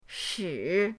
shi3.mp3